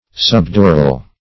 Subdural \Sub*du"ral\, a.